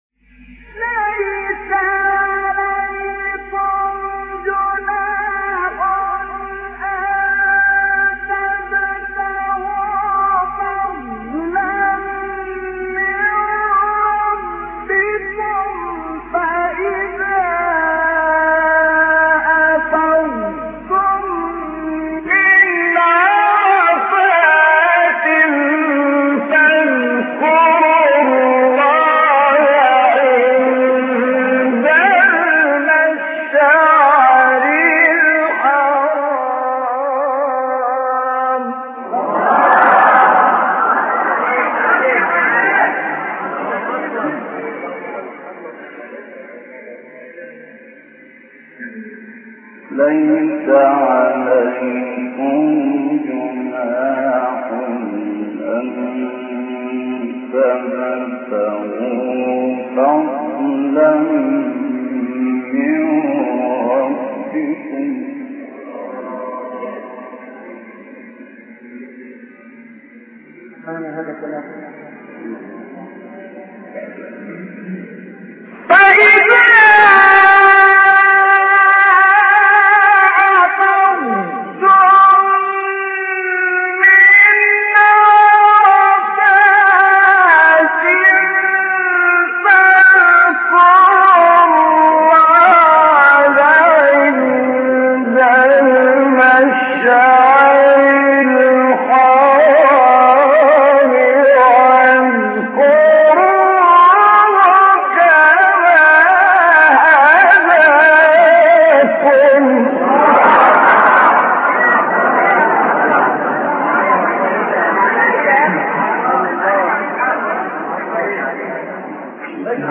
آیه 198-200 سوره بقره استاد شحات | نغمات قرآن | دانلود تلاوت قرآن